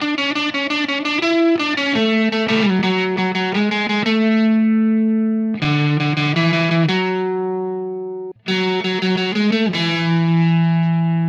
Indie Pop Guitar 02.wav